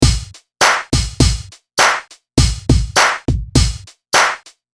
95-105 bpm
Mixture of drum machines and live instrument samples
The beat we’re constructing is relatively simple in terms of the pattern itself, but a few small tricks and clever techniques turn it into a rolling groove with a distinctive early 90s G-funk feel.
The DR-202 kick hits at a lower frequency for the thump and the Linn kick provides a nice snappy transient.
The clap follows a simple pattern, triggering on the second and fourth beat of each bar.
The closed hat is buried quite low in the mix, leaving the focus on the kick, claps and open hat.
Crucially, the tambourine is played with a very loose pattern, rushing ahead of the beat and giving the entire groove an even sloppier feel.
West Coast Hip Hop Beat - step 5
Beat-Dissected-West-Coast-Hip-Hop-Beat-Step-5.mp3